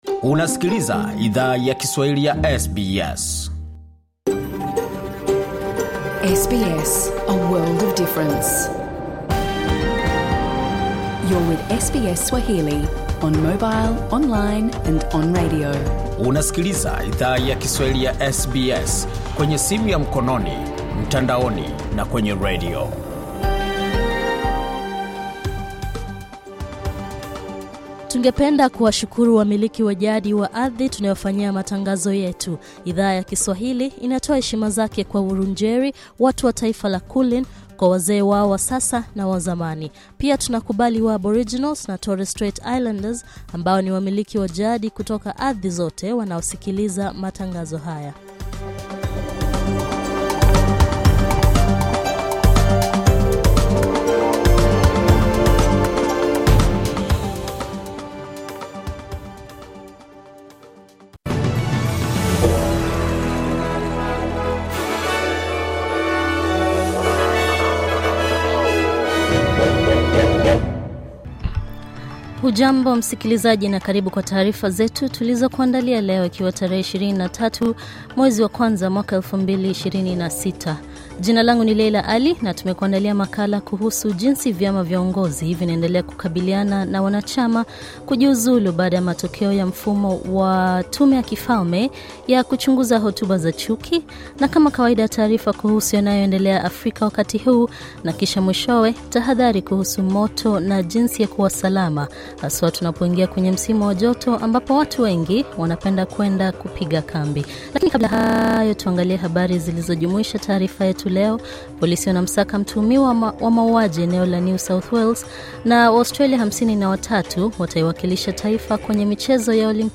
Taarifa ya habari:Wanachama wa National wajiuzulu kufuatia sheria tata za hotuba za chuki